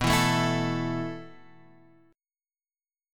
B7sus4 chord